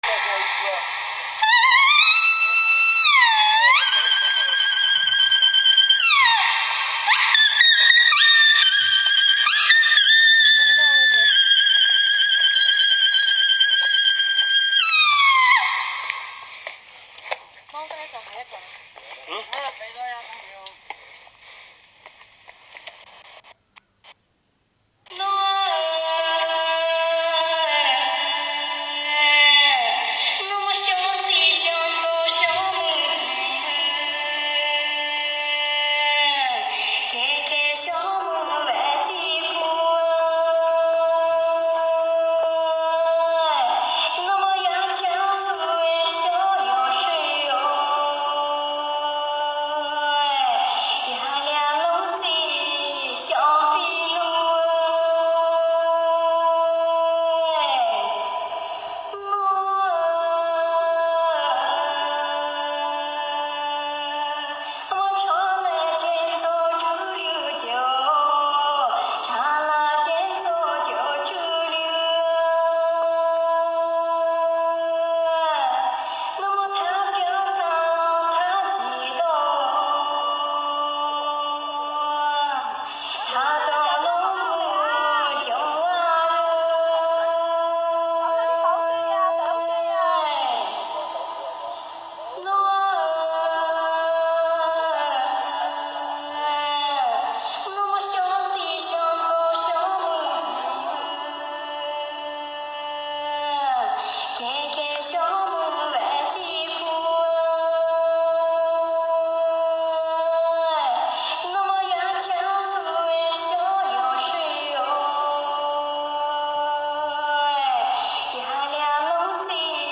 木叶配平腔.mp3